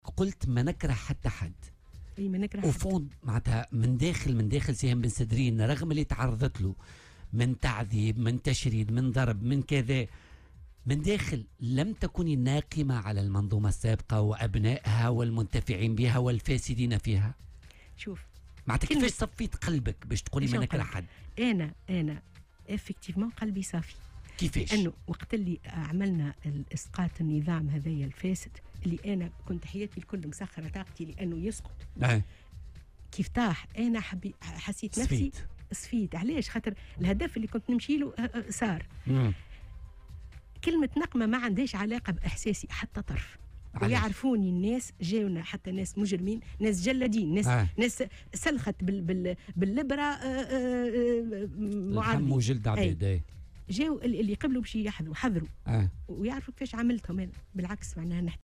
وأضافت في مداخلة لها اليوم في برنامج "بوليتيكا" على "الجوهرة أف أم" أن كل الجروح لديها قد شُفيت بمجرّد سقوط النظام السابق، وفق قولها.